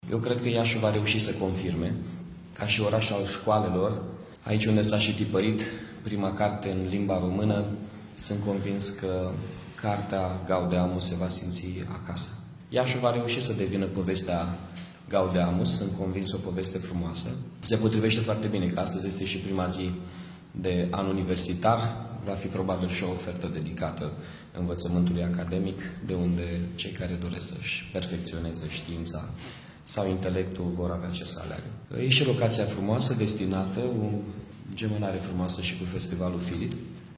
Prezent la conferinţa de presă de la Radio Iaşi, primarul Mihai Chirica a vorbit despre faptul că oraşul în care s-a tipărit prima carte în limba română trebuia să se afle pe harta Gaudeamus: